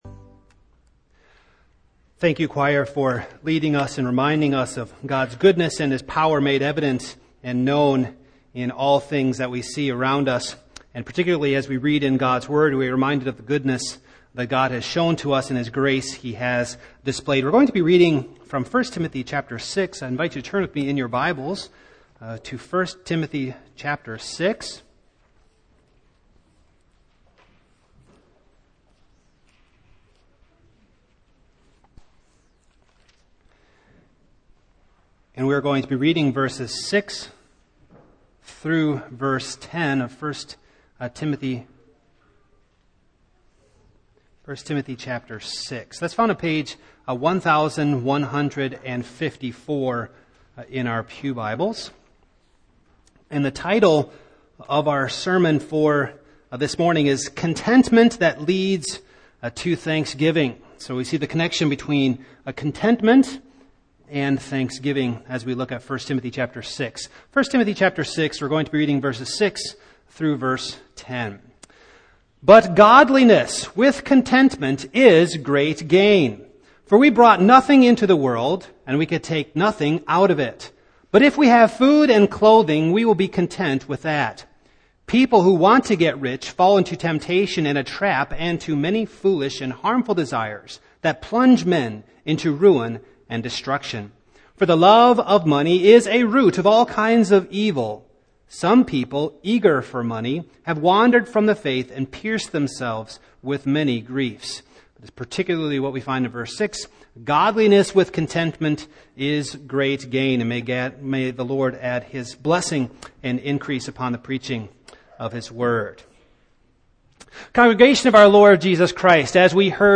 Series: Single Sermons
Service Type: Morning